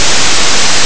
• Para um ruído branco:
ruidoBranco.wav